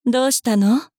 大人女性│女魔導師│リアクションボイス│商用利用可 フリーボイス素材 - freevoice4creators